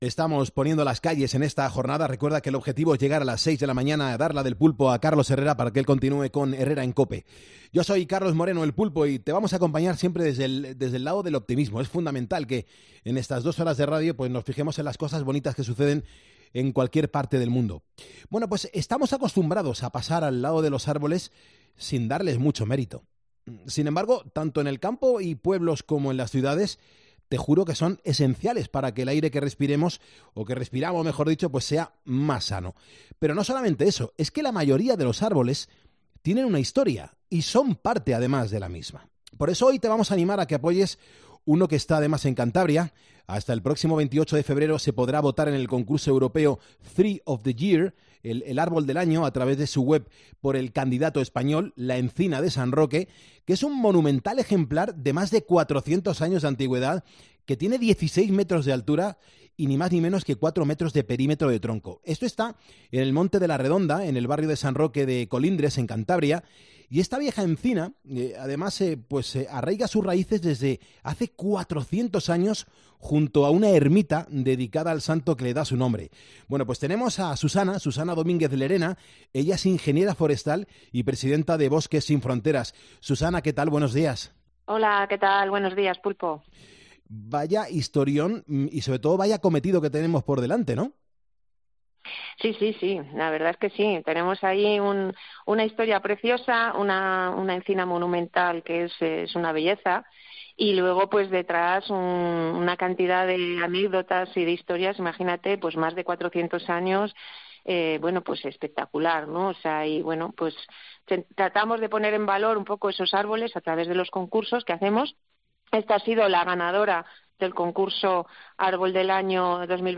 Todos los secretos de la entrevista en el audio.